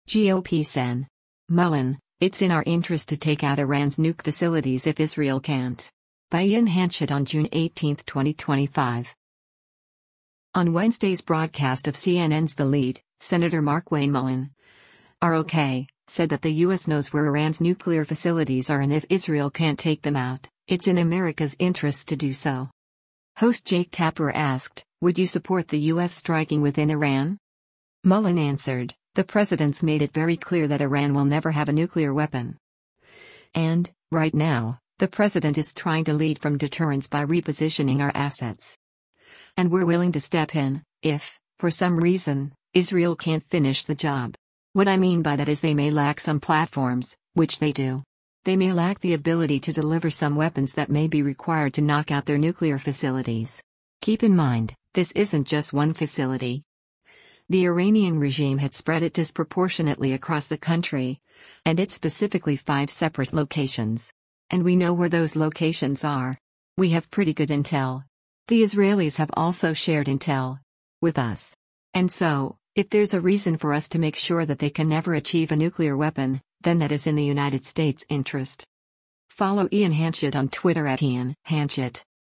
On Wednesday’s broadcast of CNN’s “The Lead,” Sen. Markwayne Mullin (R-OK) said that the U.S. knows where Iran’s nuclear facilities are and if Israel can’t take them out, it’s in America’s interests to do so.
Host Jake Tapper asked, “Would you support the U.S. striking within Iran?”